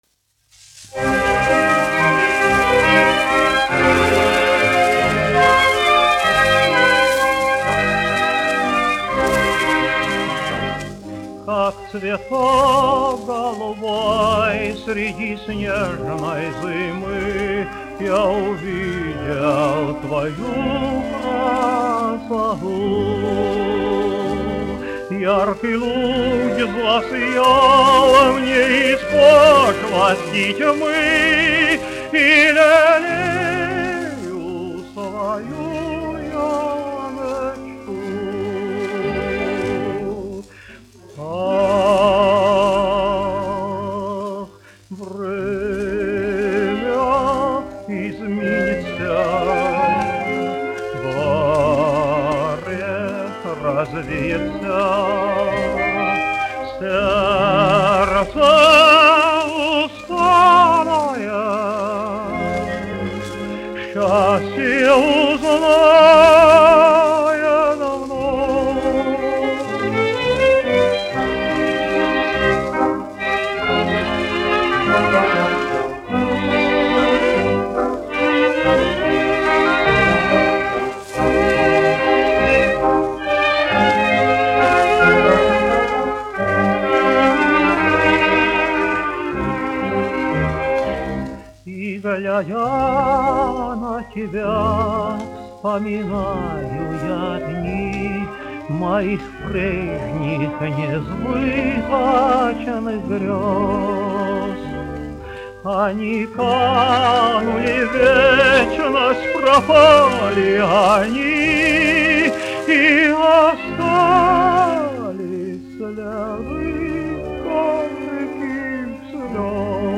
dziedātājs
1 skpl. : analogs, 78 apgr/min, mono ; 25 cm
Romances (mūzika)
Latvijas vēsturiskie šellaka skaņuplašu ieraksti (Kolekcija)